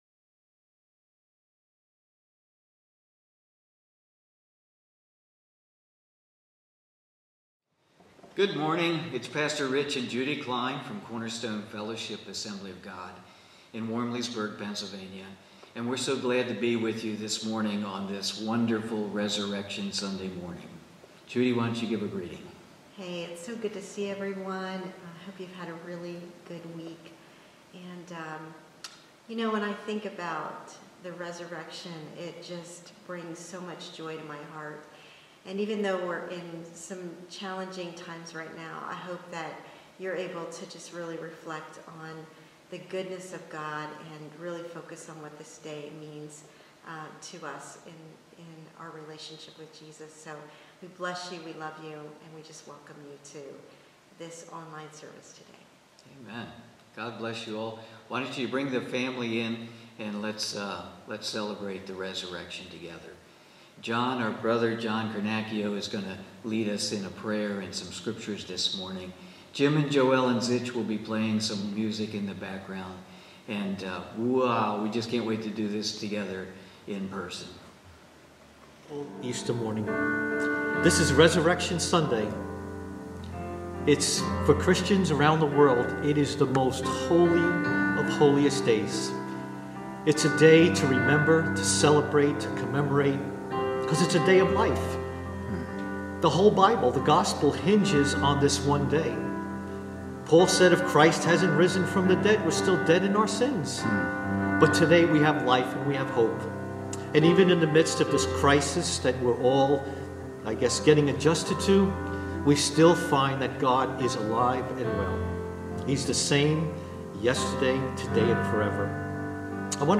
Salvation Series Abiding in Christ Book John Watch Listen Save Cornerstone Fellowship Online Service April 12, 2020, Easter Sunday.